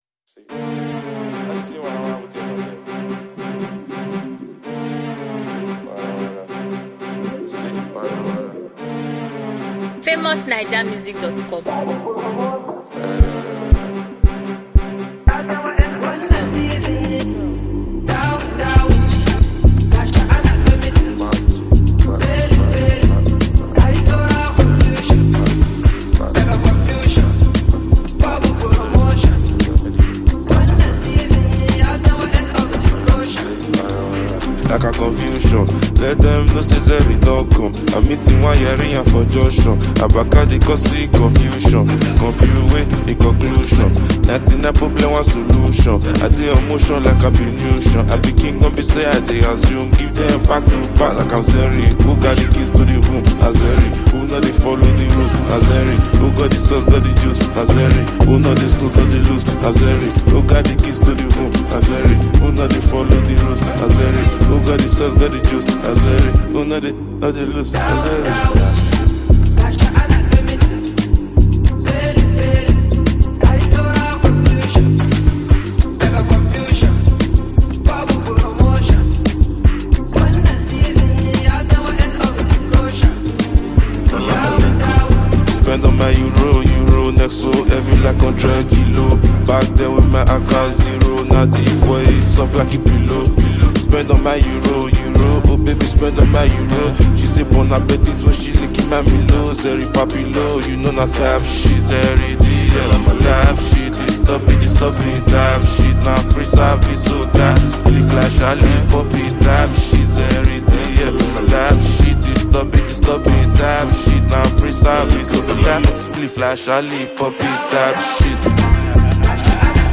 Nigerian Afrobeat